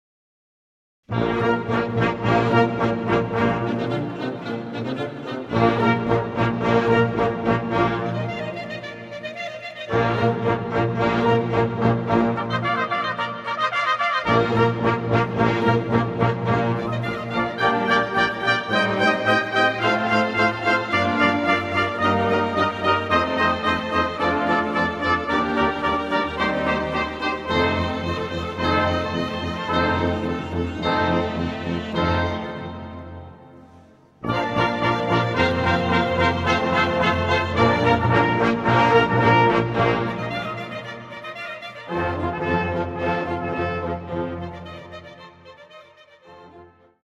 Gattung: Eröffnungschor
Besetzung: Blasorchester